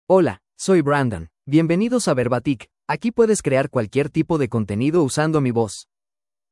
MaleSpanish (United States)
Voice sample
Male
Spanish (United States)
Brandon delivers clear pronunciation with authentic United States Spanish intonation, making your content sound professionally produced.